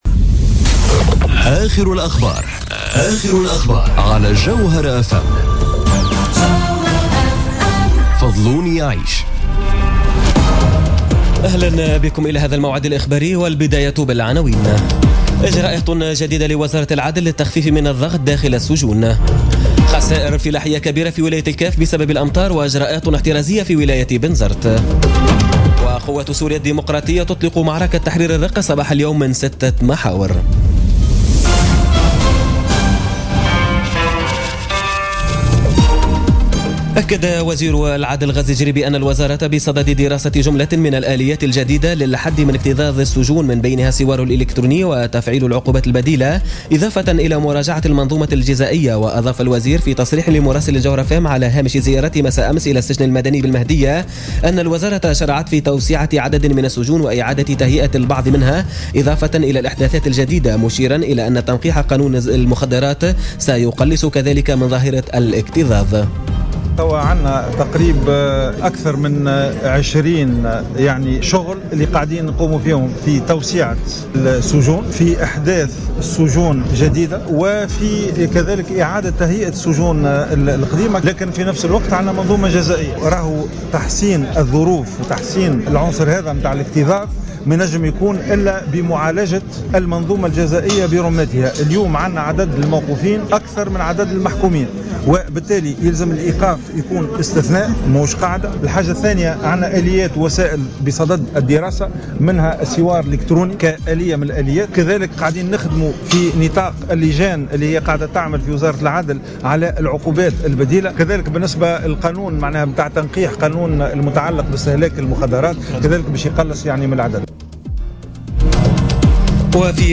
نشرة أخبار منتصف الليل ليوم الثلاثاء 6 جوان 2017